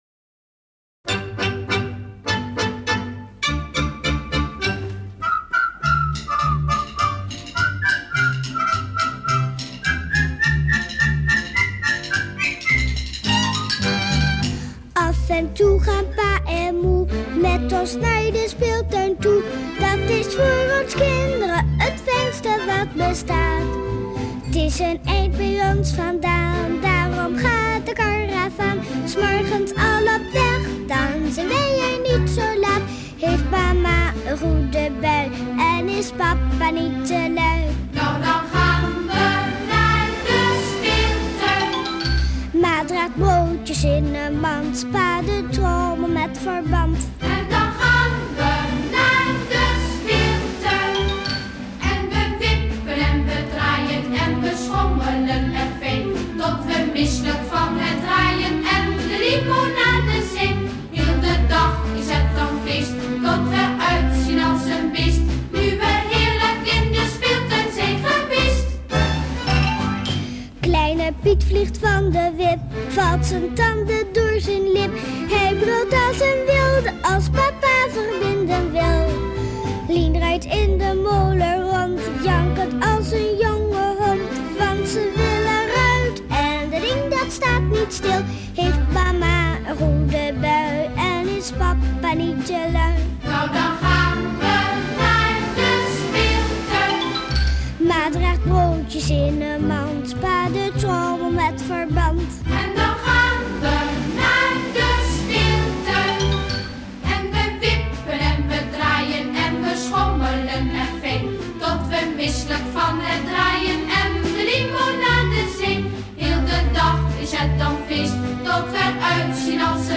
Nederlands kinderliedje met handgebaren.
muziek lp  /  of klik hier